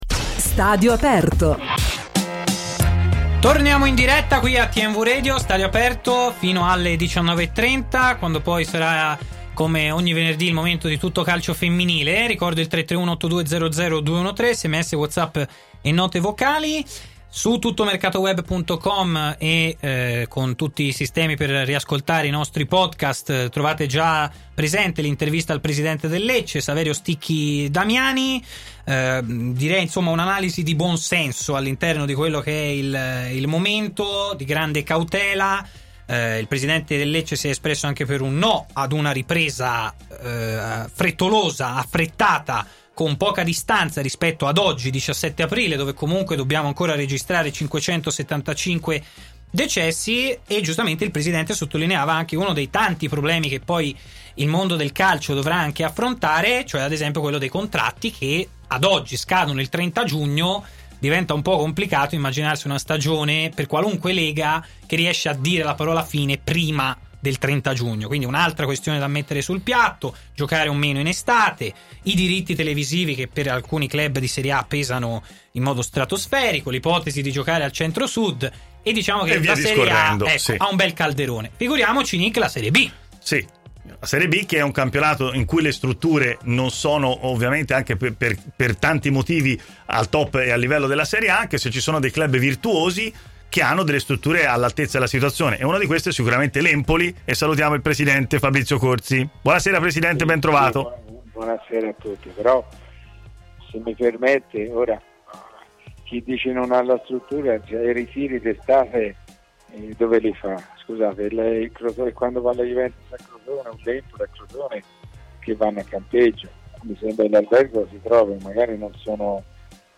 ha parlato nel corso di Stadio Aperto, trasmissione in onda su TMW Radio